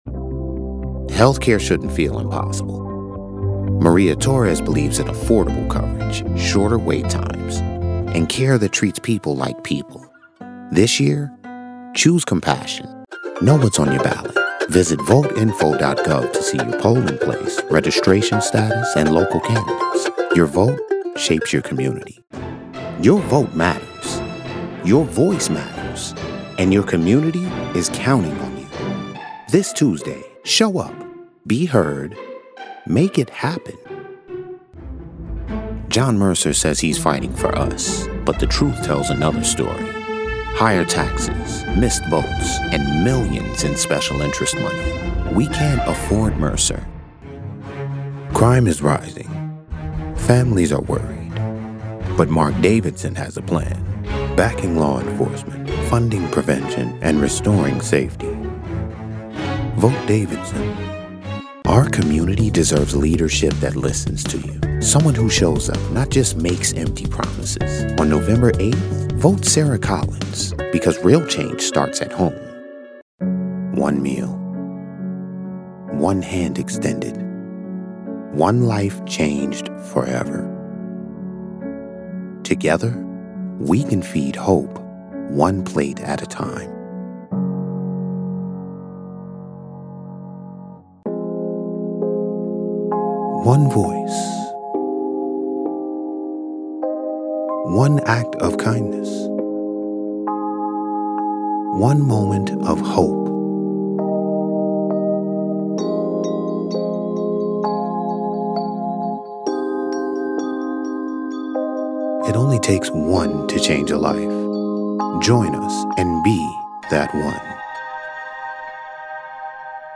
Style in every syllable. Depth in tone.
Political Demo